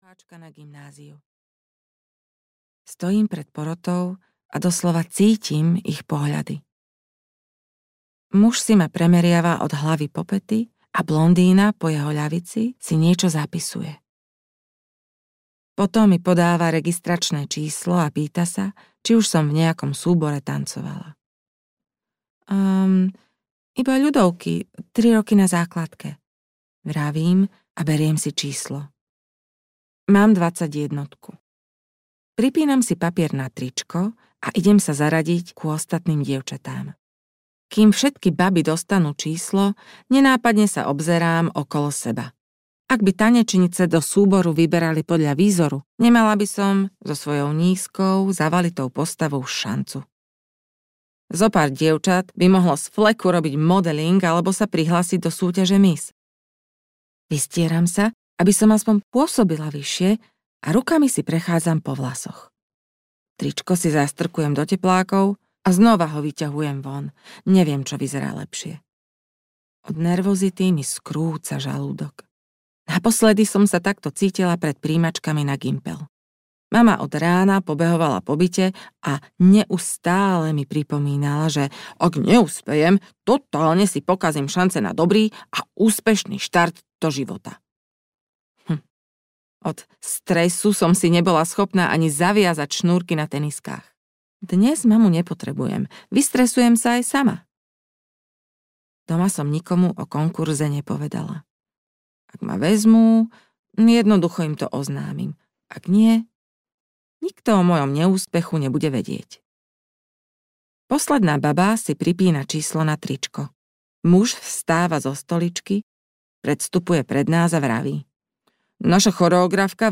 Ukázka z knihy
hladna-dusa-audiokniha